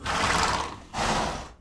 horsesnort.wav